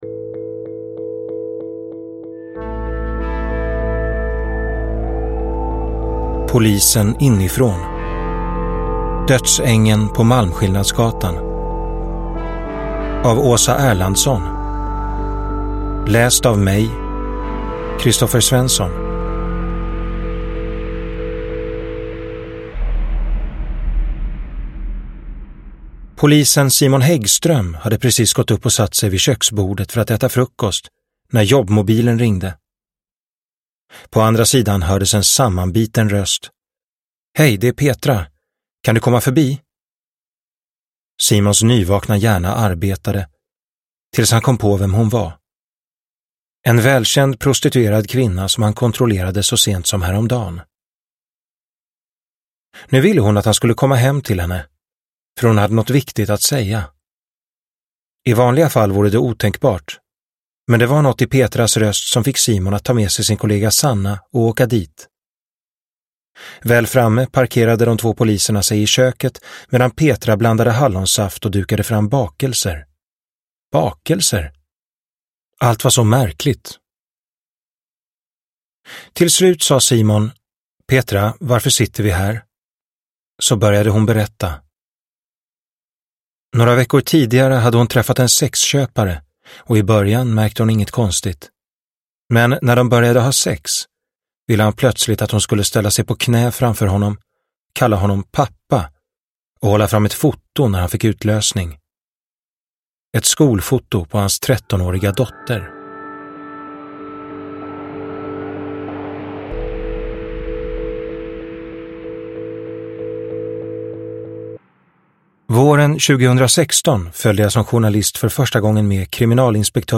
Dödsängeln på Malmskillnadsgatan – Ljudbok – Laddas ner